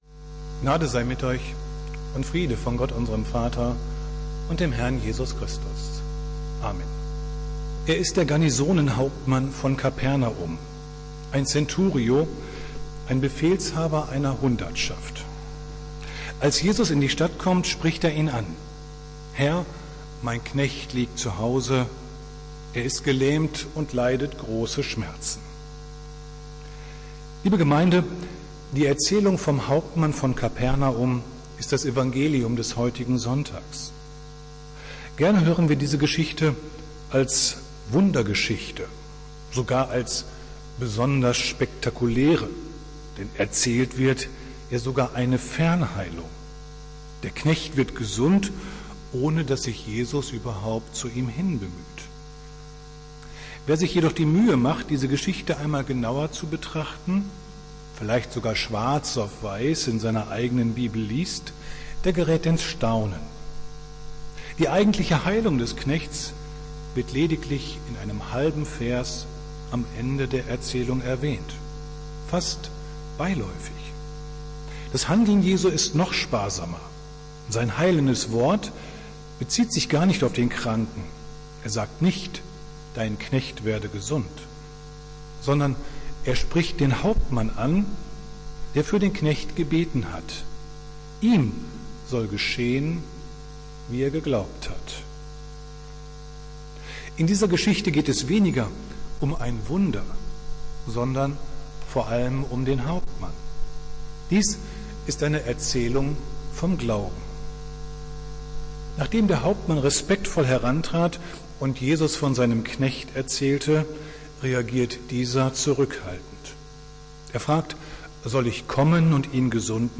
Predigt des Gottesdienstes aus der Zionskirche vom Sonntag, 23.01.2022
Wir haben uns daher in Absprache mit der Zionskirche entschlossen, die Predigten zum Nachhören anzubieten.